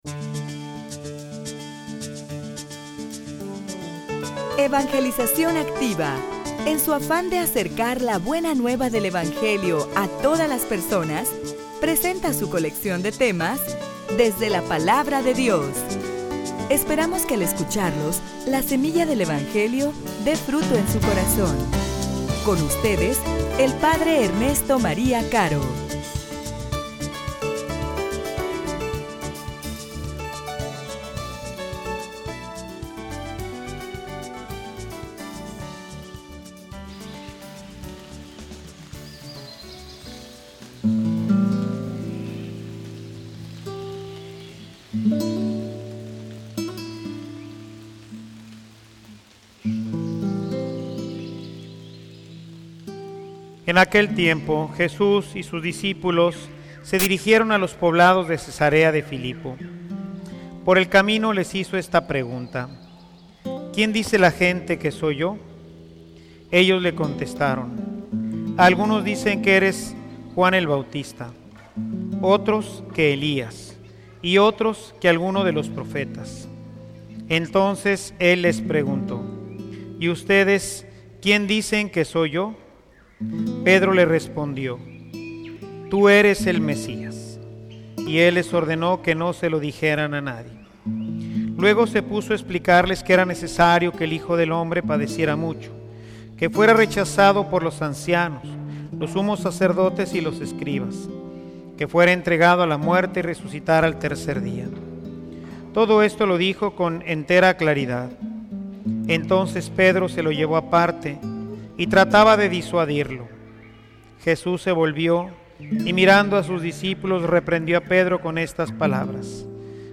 homilia_Toma_tu_cruz_y_sigueme.mp3